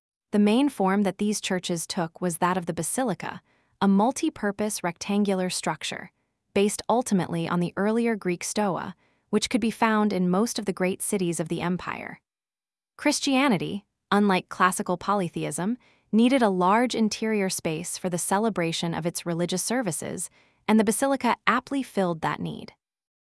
Text-to-Speech